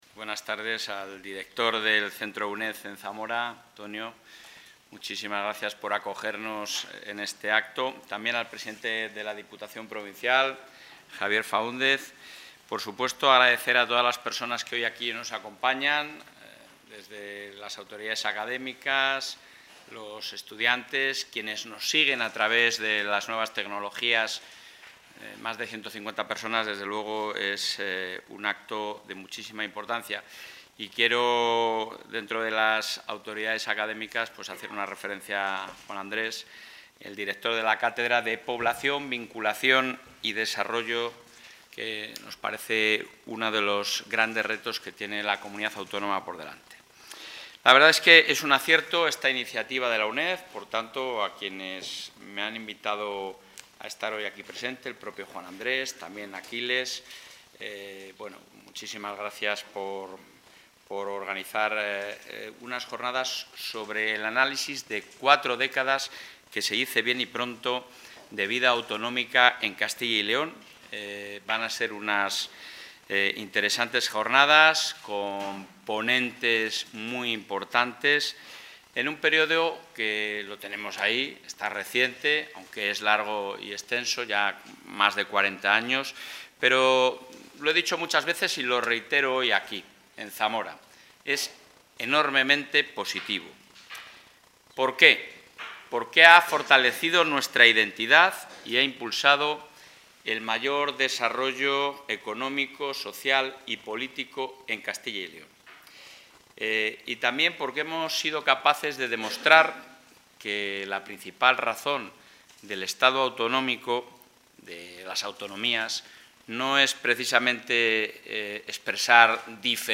El presidente de la Junta de Castilla y León, Alfonso Fernández Mañueco, ha participado hoy en la inauguración del ciclo...
Intervención del presidente de la Junta.